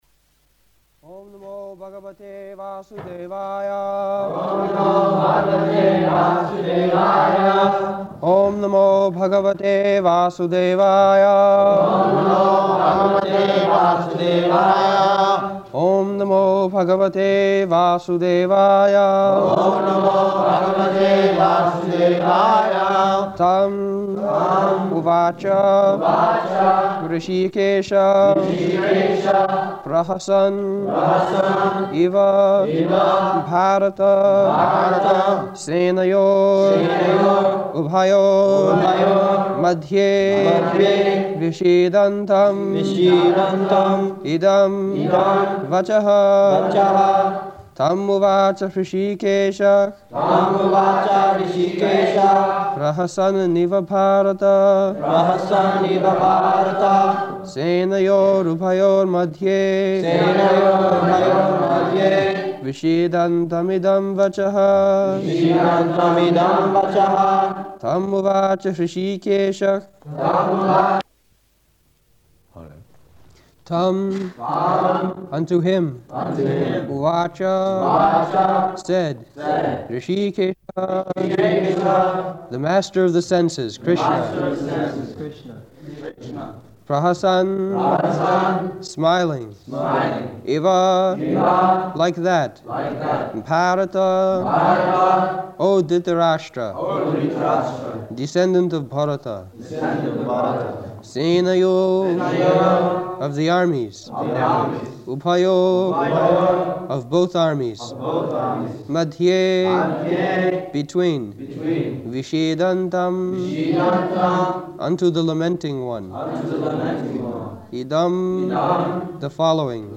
August 16th 1973 Location: London Audio file